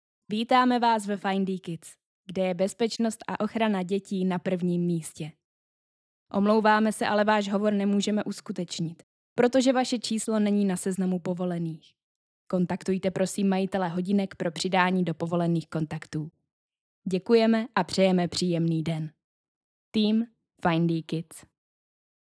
Profesionální ženský hlas - voiceover/dabing (do 400 znaků)